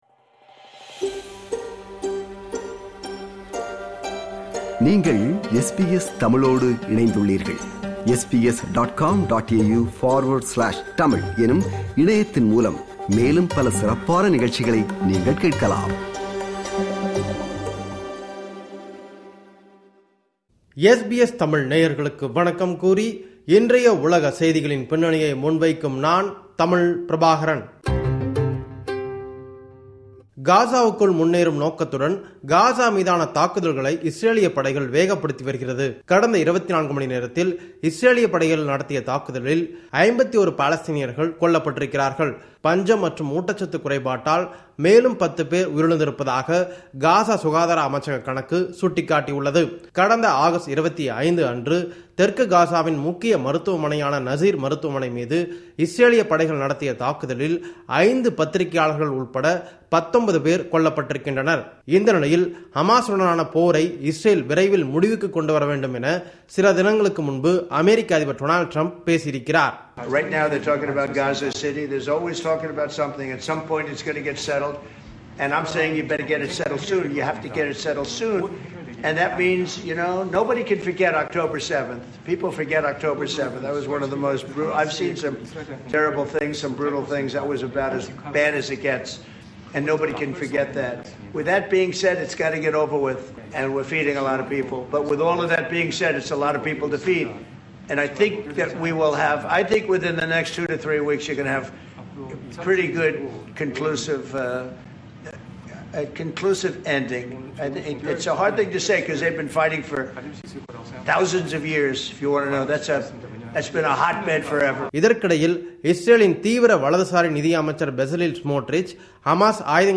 இந்த வார உலக செய்திகளின் தொகுப்பு